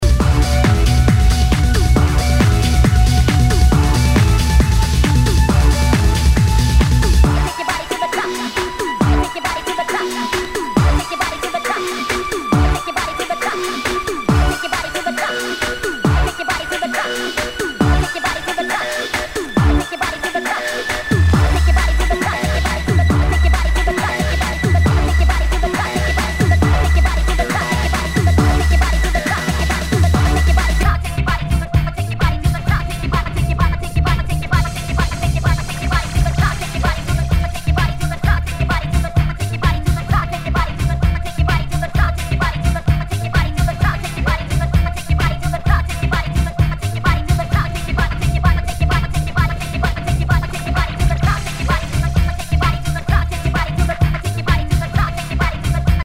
HOUSE/TECHNO/ELECTRO
ナイス！テック・ハウス！